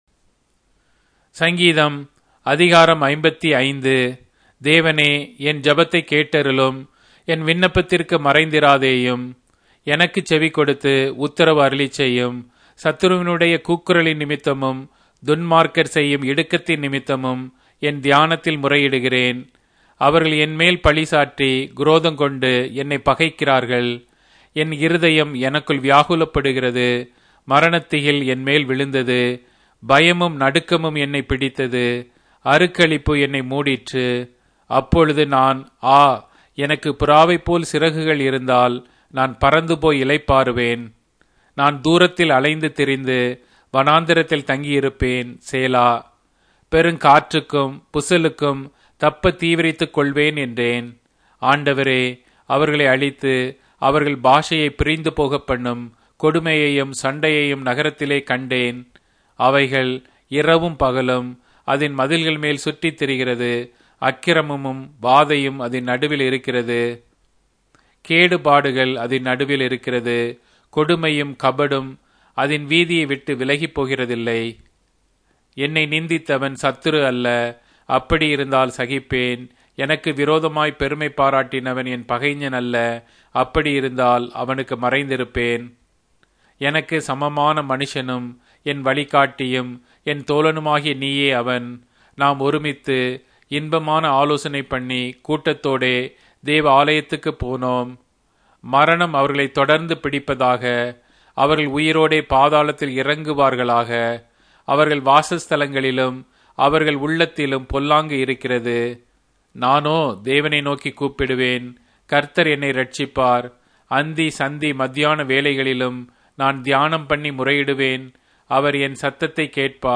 Tamil Audio Bible - Psalms 62 in Lxxen bible version